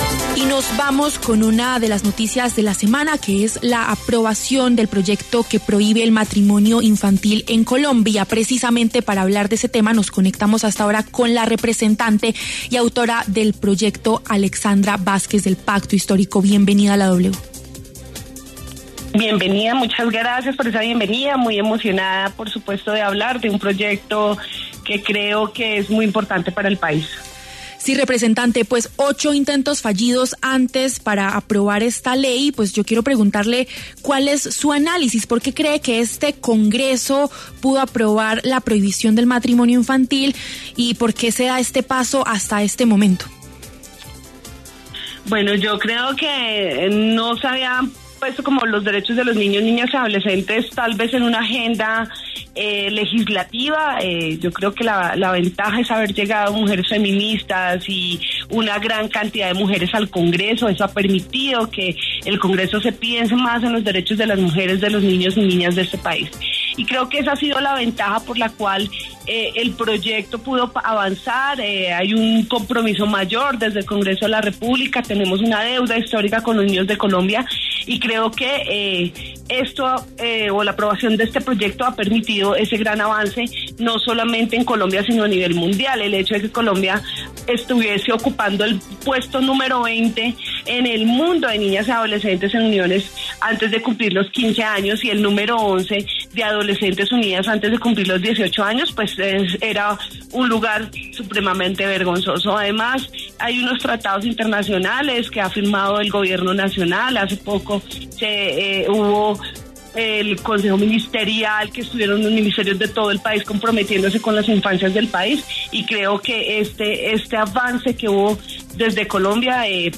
Escuche la entrevista a la representante Alexandra Vásquez en W Fin de Semana: